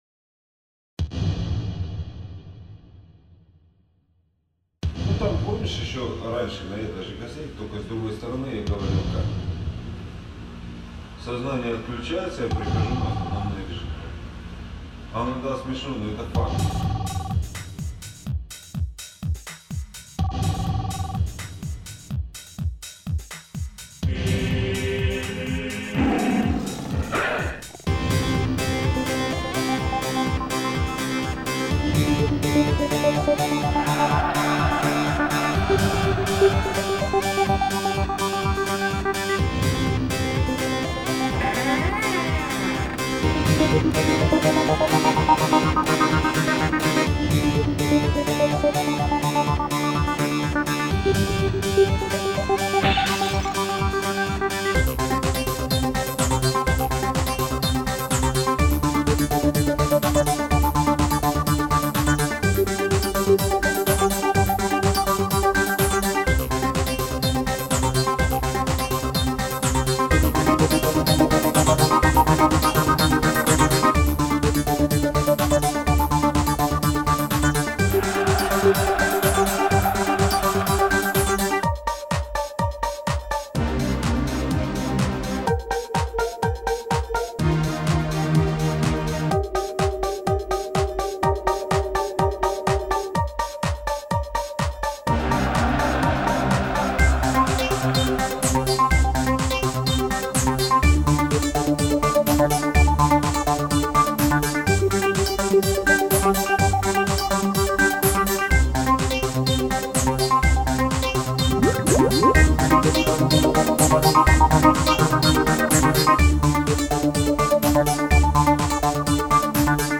Музыкальные файлы записаны с помощью софта под общим названием ACID, речевые с помощью говорилки и голосового движка Алены.
piano_new.mp3